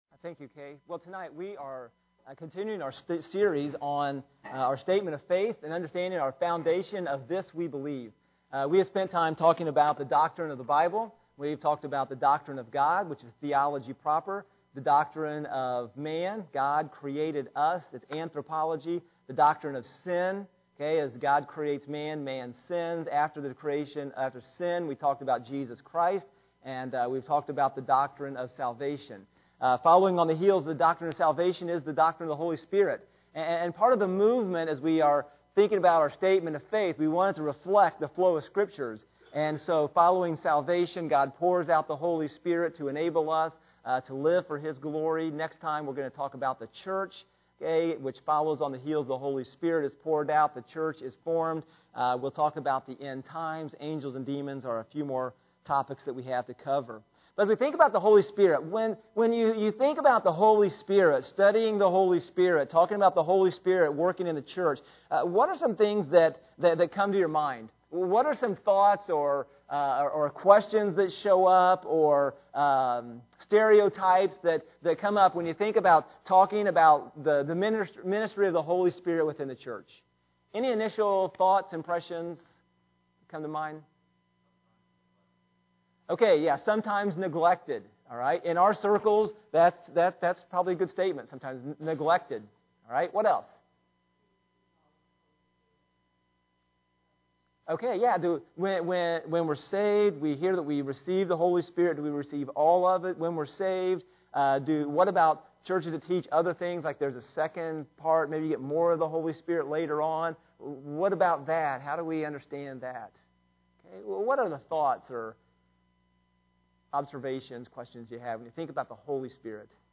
Sermons Archive - Page 33 of 36 - East Side Baptist Church